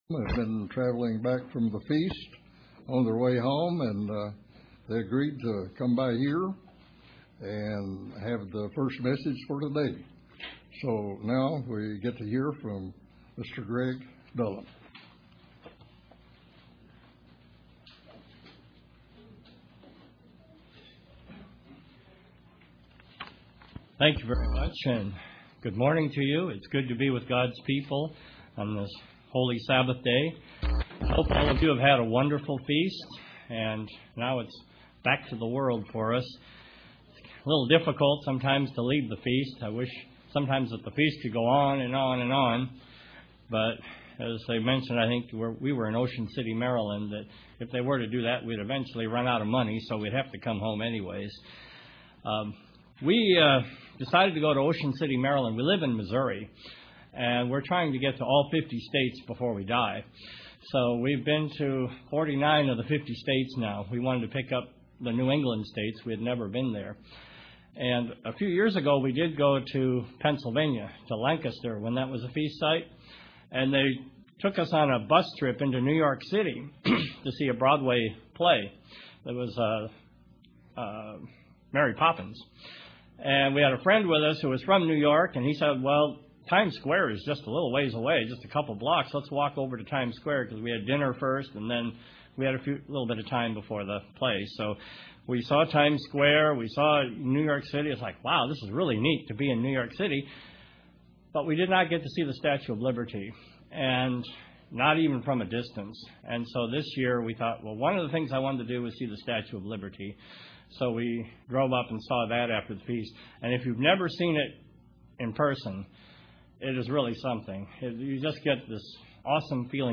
This sermon focuses on the character of the man, Lot.
Given in Paintsville, KY